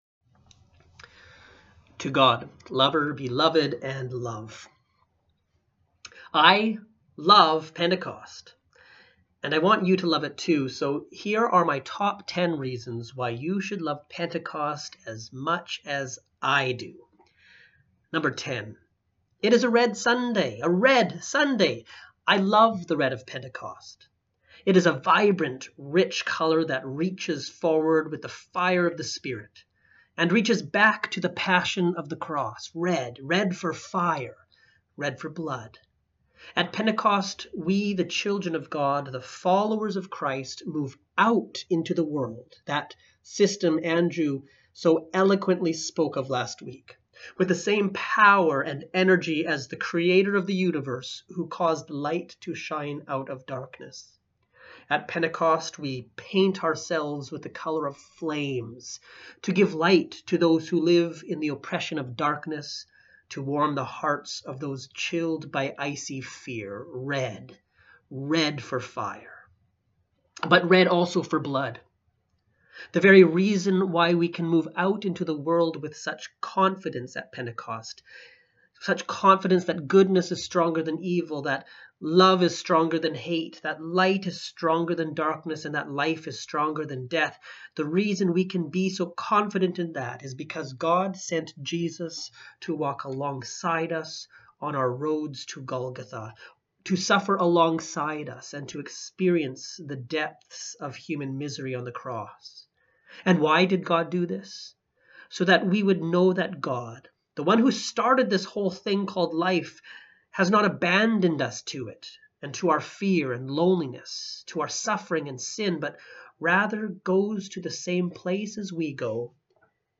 Sermons | St. Dunstan's Anglican